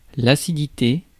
Ääntäminen
Synonyymit pH Ääntäminen France: IPA: [l‿a.si.di.te] Tuntematon aksentti: IPA: /a.si.di.te/ Haettu sana löytyi näillä lähdekielillä: ranska Käännös 1. acidez {f} Suku: f .